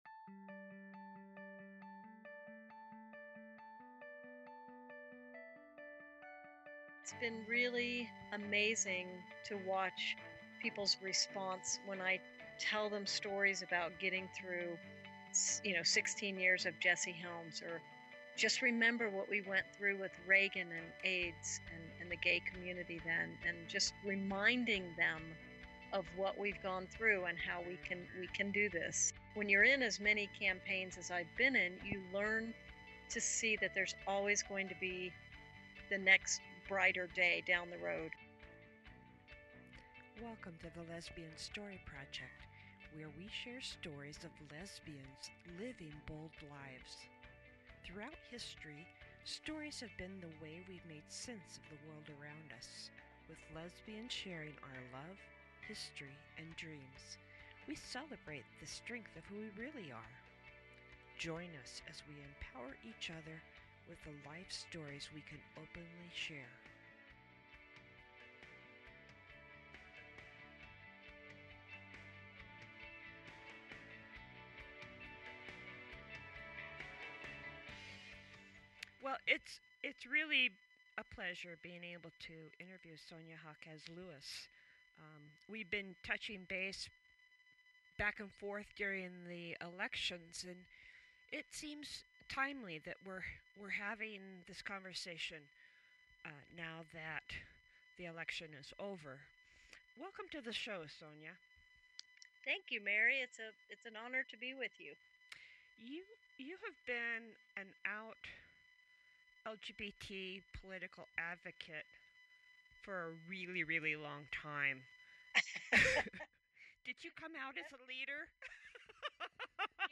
Sonya Jaquez Lewis has been active in LGBT and political issues for many years. In this interview, she shares her perspective on the challenges we've faced and looks to the future.